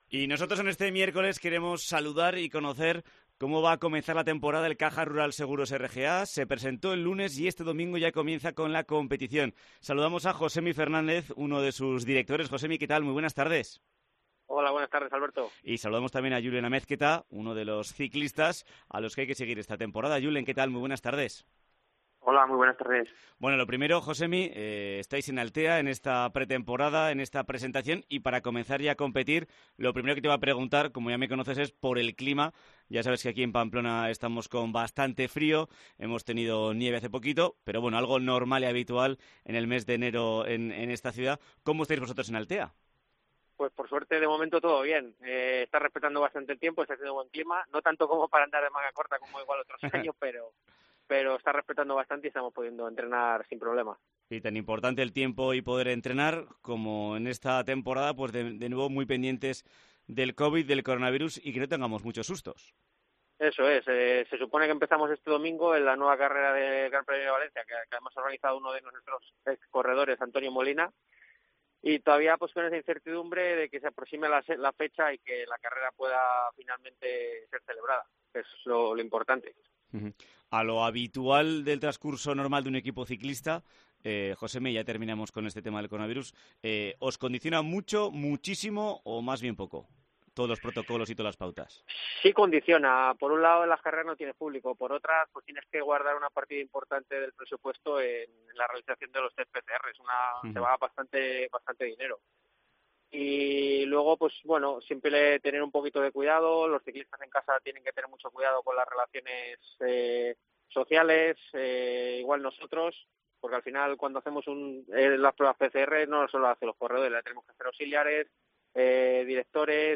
Entrevista en COPE Navarra